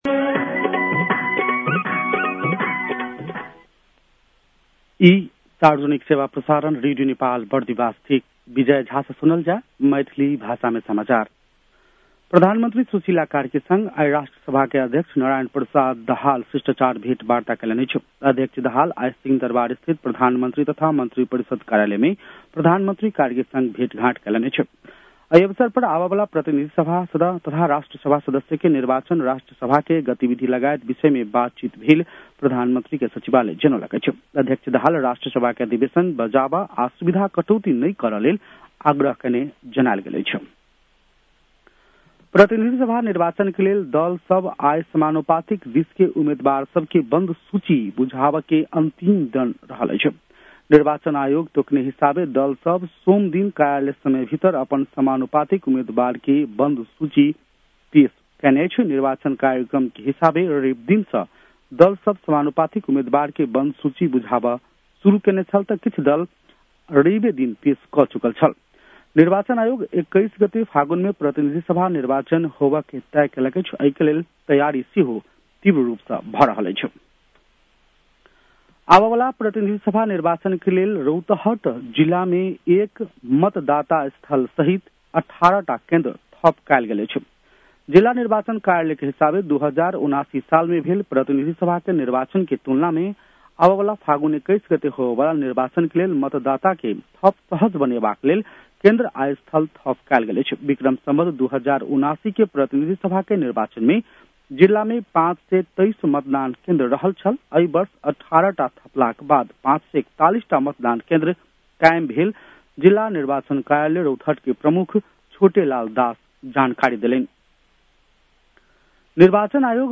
मैथिली भाषामा समाचार : १४ पुष , २०८२
6.-pm-maithali-news-1-6.mp3